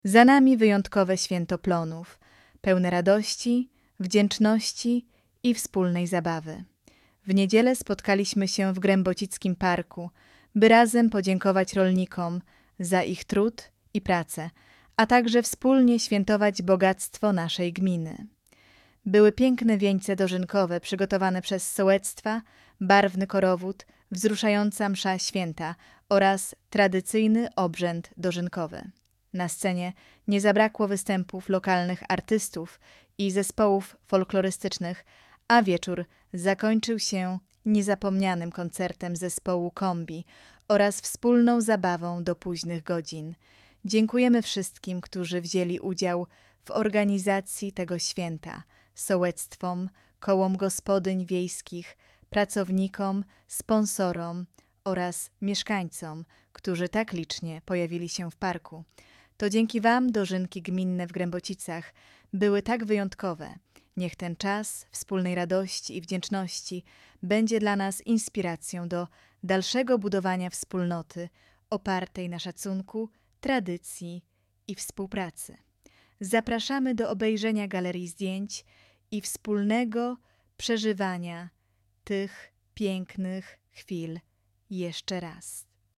lektor-do-Dozynek.mp3